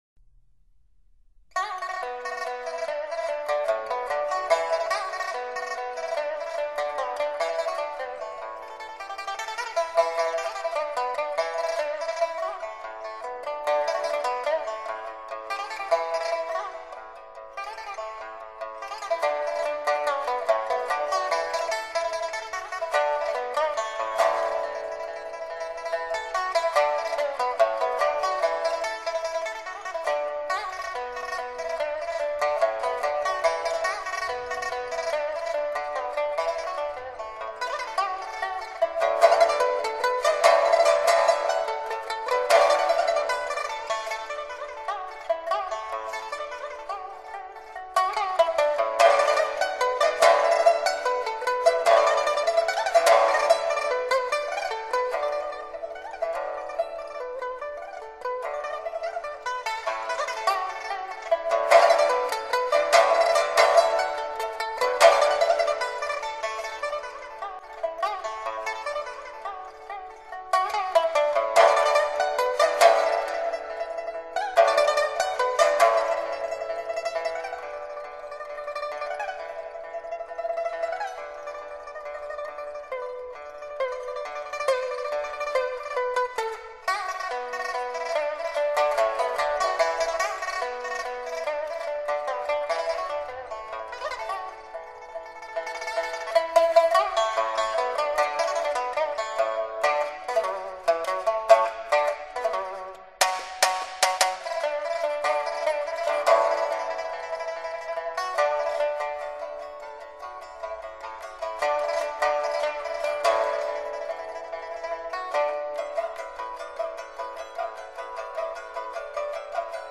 录音地点：北京音乐厅